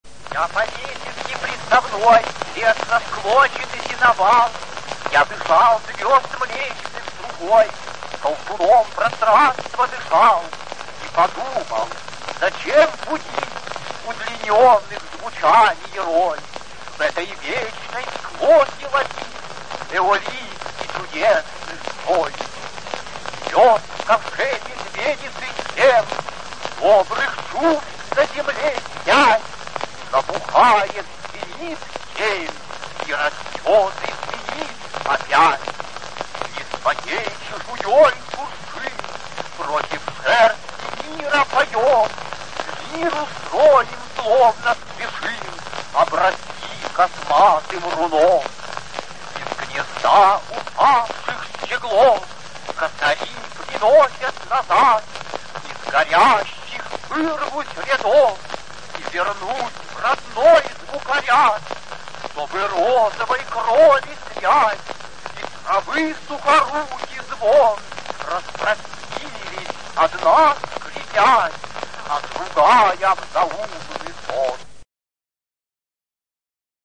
8. «О.Э. Мандельштам (исполняет автор) – Я по лесенке приставной…» /